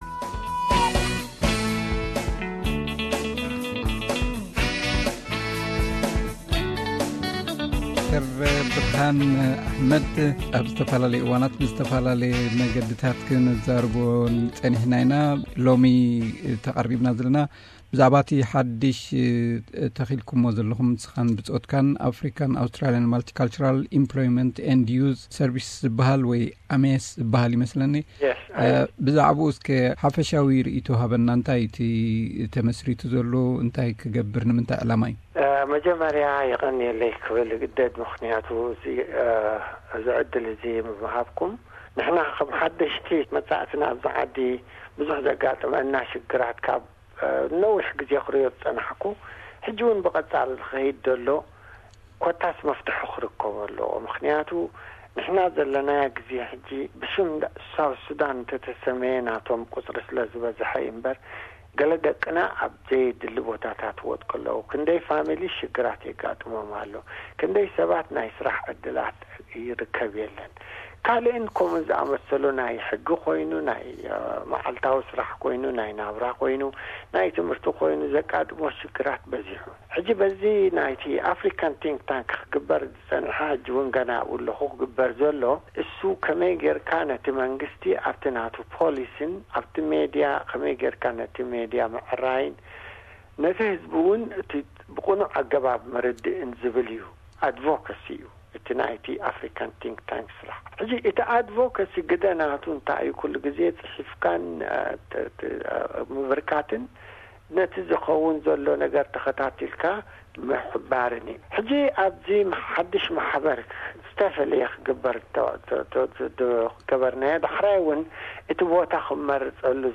ቃለ መሕትት